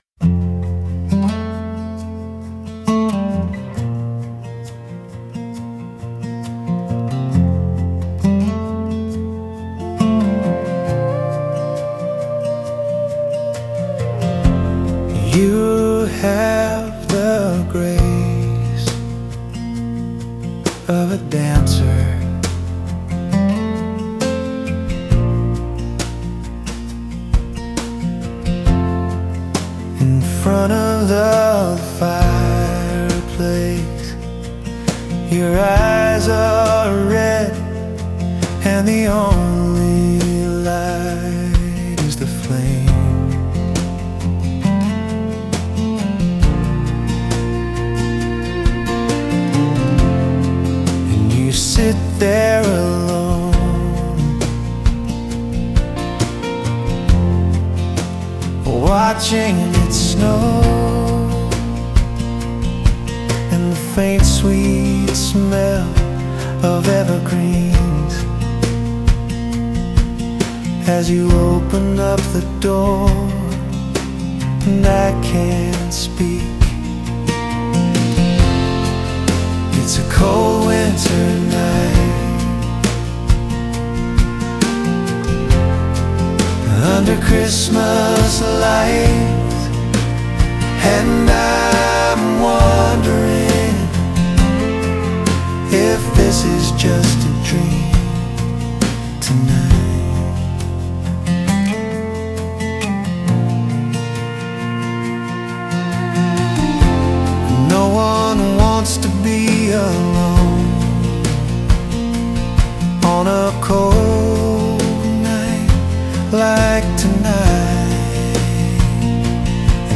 Christmas music